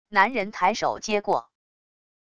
男人抬手接过wav音频